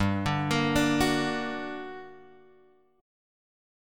G Minor Major 7th